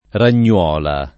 ragnuola [ ran’n’ U0 la ]